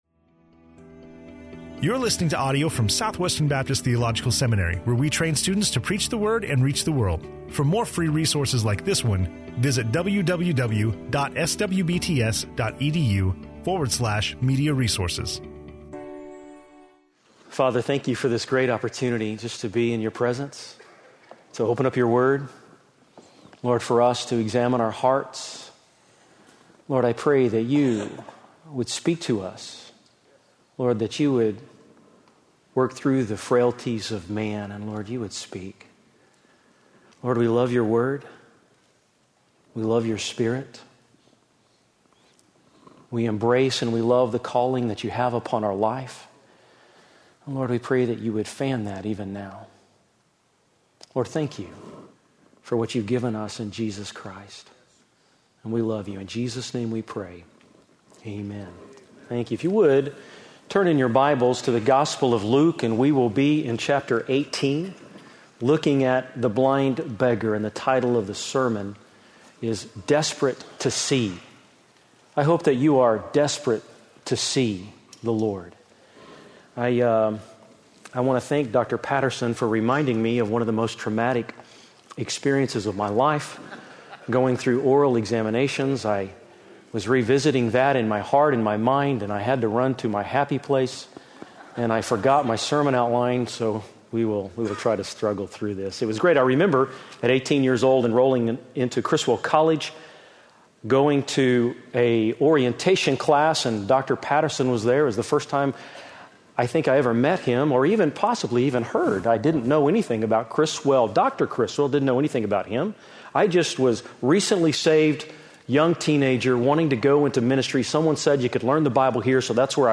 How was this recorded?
on Luke 18:35-43 in SWBTS Chapel